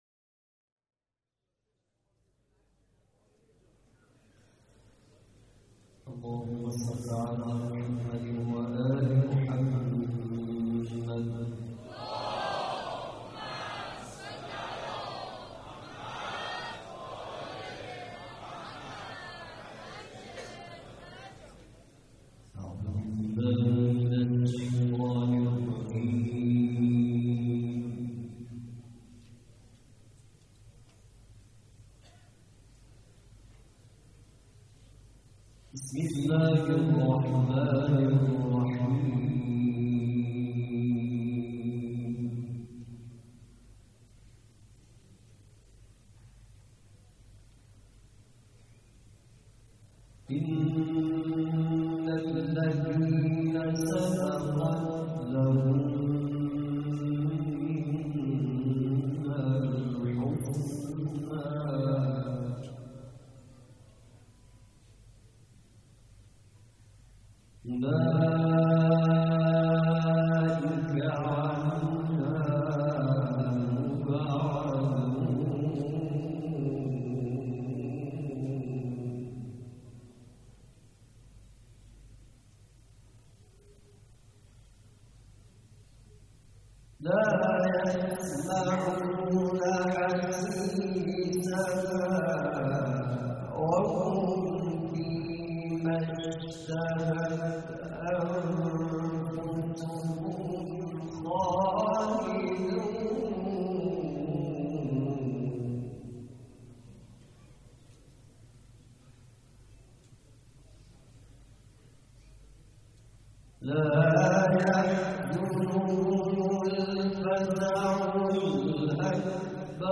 قرائت قرآن
قرائت قرآن کریم
مراسم عزاداری شب اول